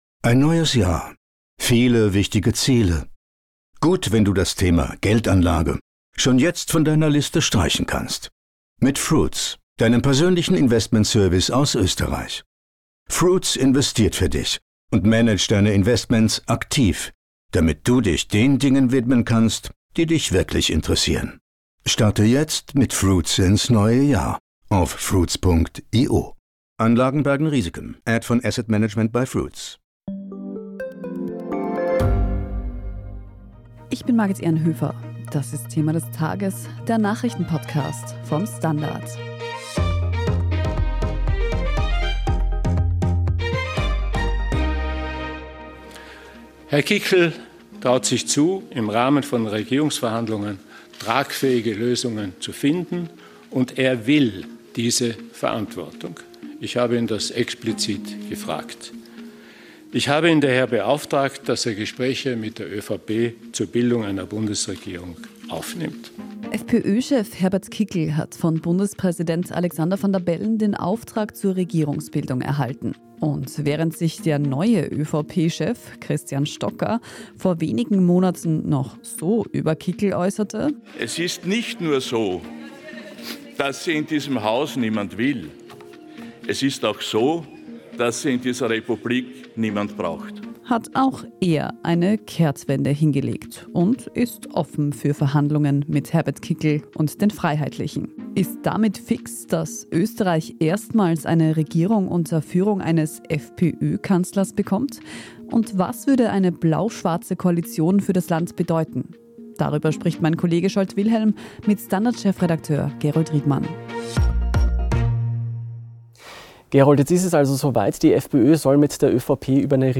"Thema des Tages" ist der Nachrichten-Podcast vom STANDARD.